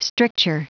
Prononciation du mot stricture en anglais (fichier audio)
Prononciation du mot : stricture